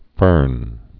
(fîrn)